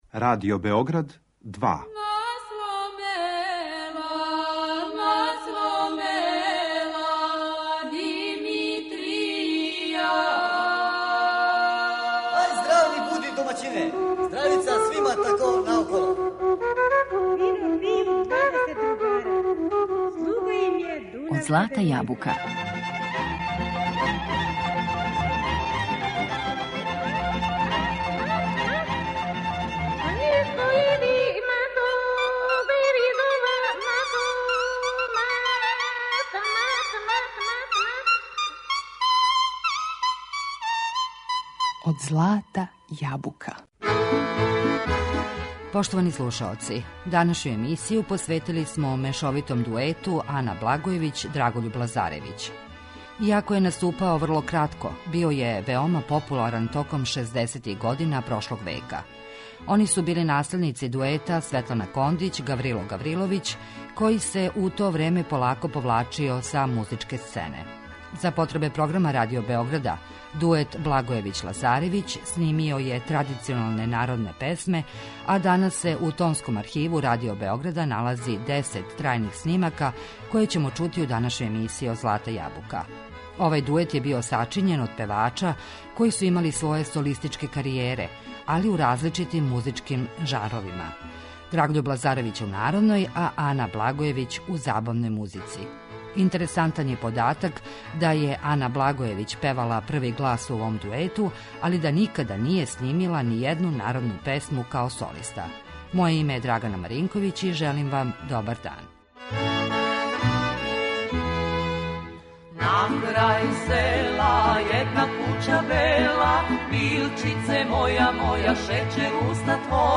Данашњу емисију посветили смо мешовитом дуету
За потребе програма Радио Београда, овај дует је снимао традиционалне народне песме, а данас у Тонском архиву налази се десет трајних снимака, које ћемо чути у данашњој емисији.